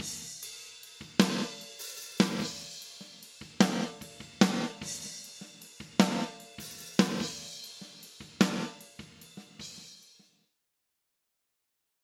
Gated-Reverb.mp3